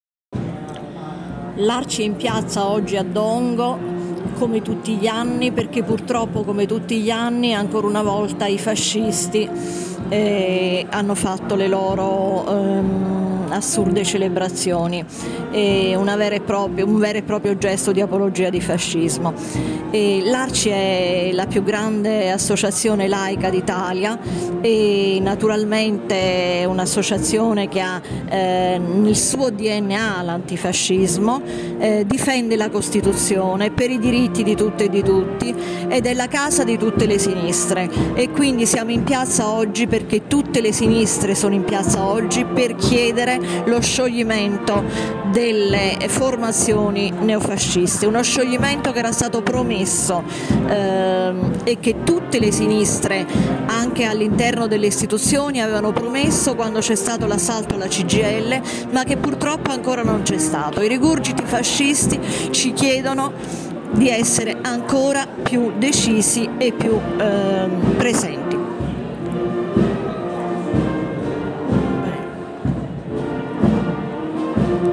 Dichiarazioni alla fine della manifestazione